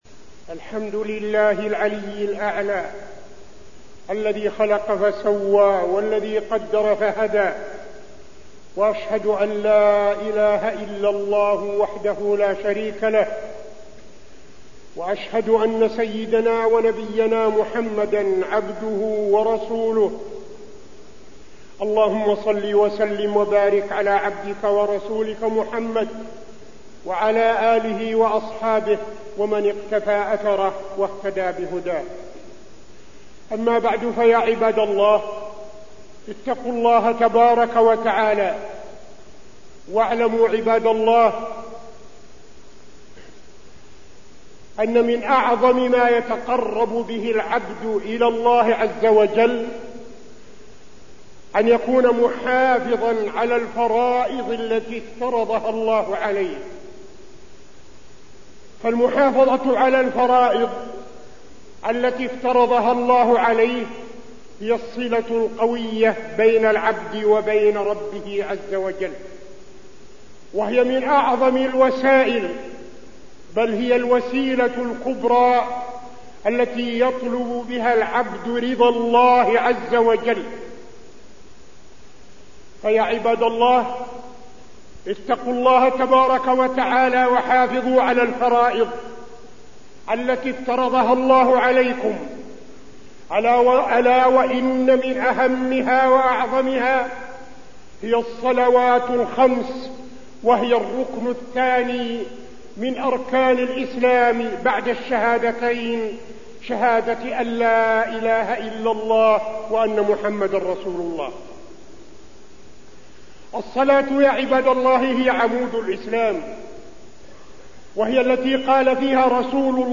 تاريخ النشر ١٢ ذو الحجة ١٤٠٤ هـ المكان: المسجد النبوي الشيخ: فضيلة الشيخ عبدالعزيز بن صالح فضيلة الشيخ عبدالعزيز بن صالح المحافظة على الصلاة